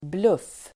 Uttal: [bluf:]